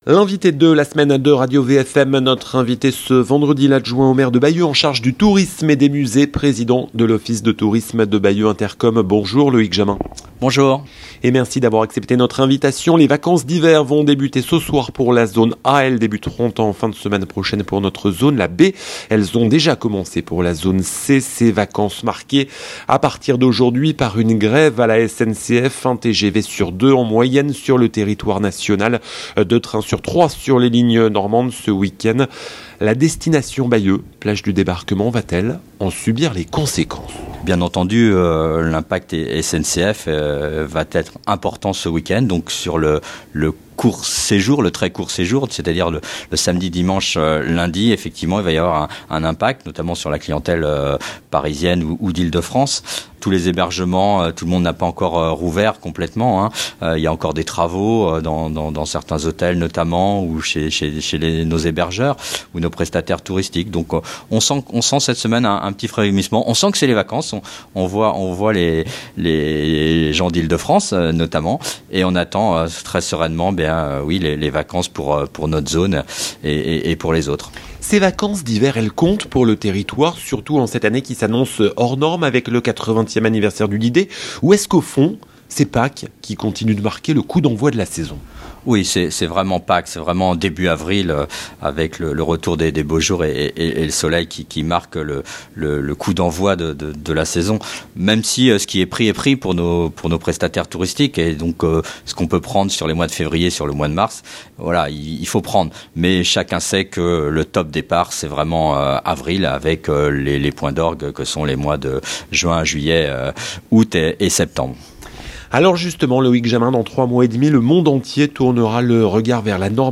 16.02.24 Loïc Jamin, l'adjoint au maire de Bayeux en charge du tourisme, des musées, de l'attractivité et de la valorisation du patrimoine est l'invité de la rédaction de Radio VFM ce vendredi 16 février 2024. Conséquences de la grève à la SNCF sur les vacances d'hiver, préparatifs du 80e anniversaire du D-Day, polémique autour du nouveau musée de la Tapisserie de Bayeux, l'élu de la capitale du Bessin, président de l'office de tourisme intercommunal répond à toutes nos questions.